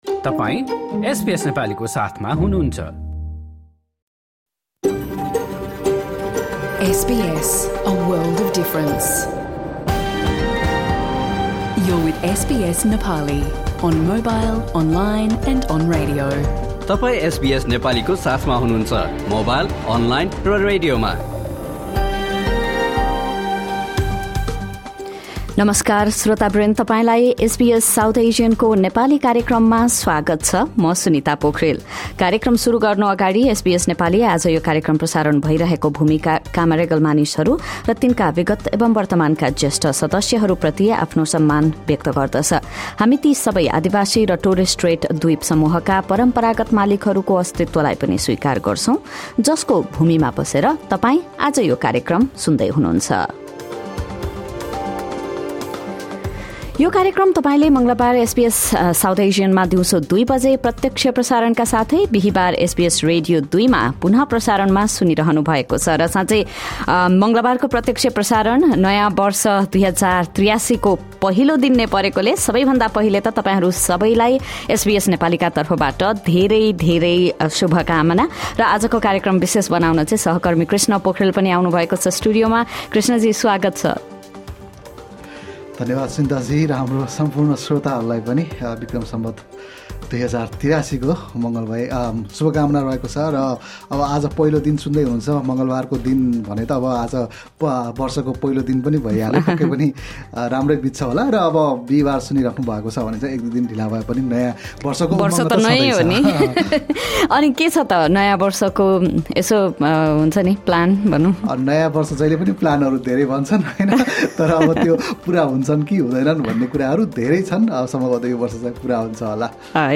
SBS Nepali broadcasts a radio program every Tuesday and Thursday at 2 PM on SBS South Asian digital radio and channel 305 on your TV, live from our studios in Sydney and Melbourne.
Listen to the SBS Nepali radio program, first aired on SBS South Asian on Tuesday, 14 April 2026.